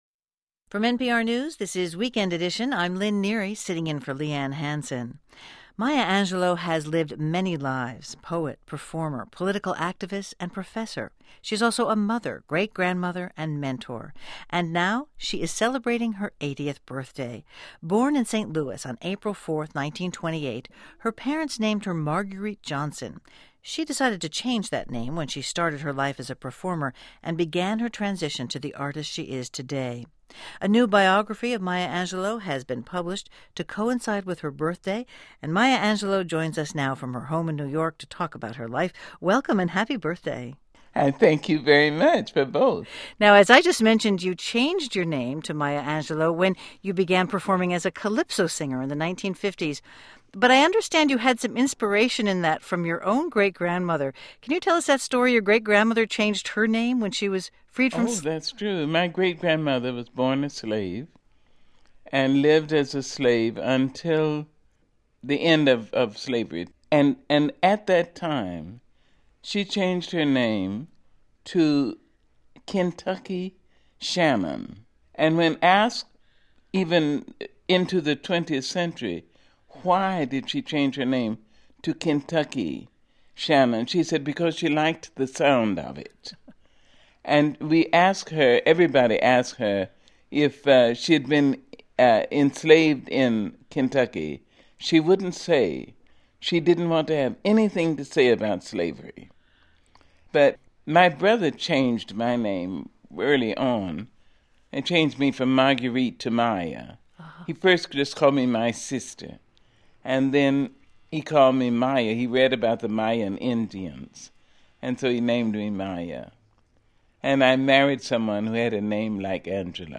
Click on the link here for Audio Player – NPR – Weekend Edition – 80th Birthday Interview with Maya Angelou April 6, 2008
While the news and reactions pour in from every part of the world, I thought I would add an interview NPR did with her in 2008, on the occasion of her 80th birthday as tribute to this gifted and treasured soul.
Maya-Angelou-Interview-2008.mp3